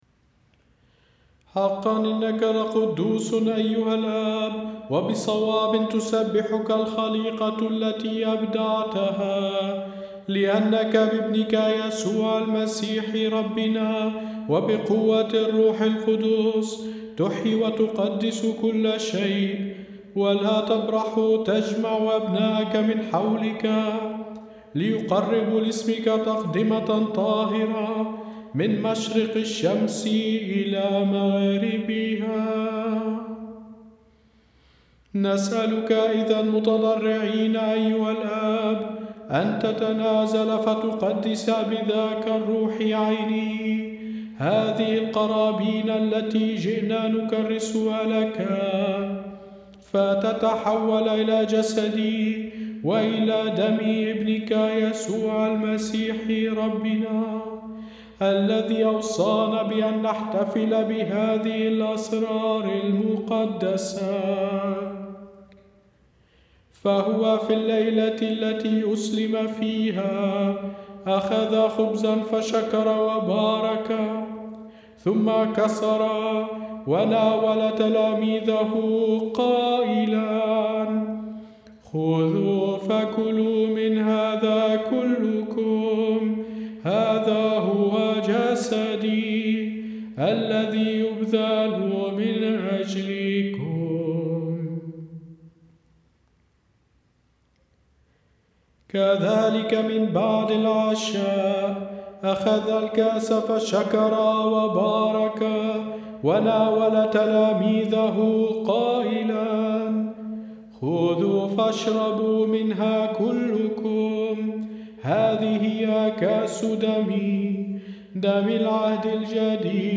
P-693-Eucharistic-Prayer-III-Chant-All.mp3